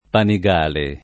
Panigale [ pani g# le ]